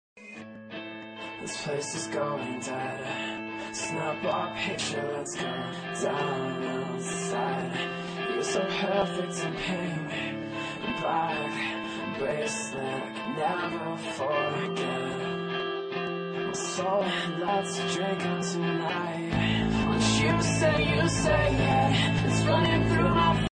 Pop & Rock